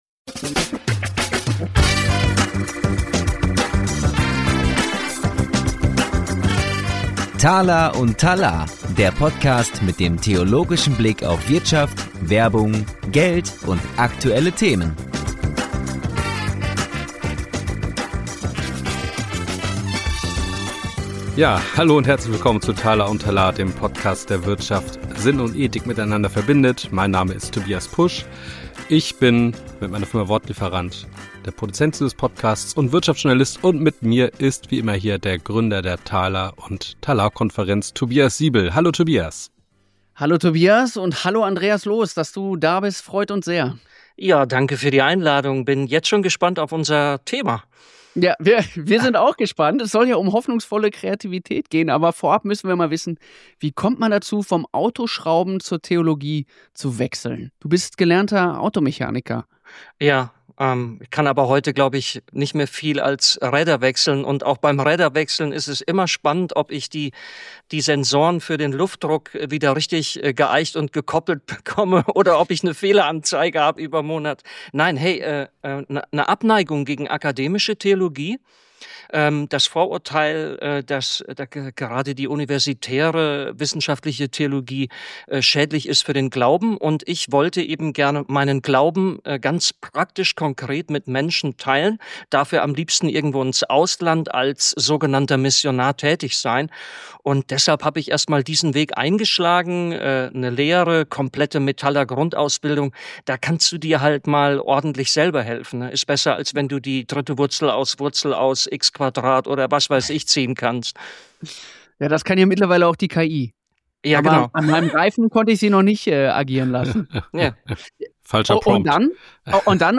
Wir diskutieren, warum unsere aktuelle „Macher-Mentalität“ oft an Grenzen stößt und warum Hoffnung eine härtere Währung ist als billiger Optimismus. Ein Gespräch über Kontrolle, das Wagnis der Freiheit (von Kindererziehung bis KI) und die Kraft des „Trotzdem“.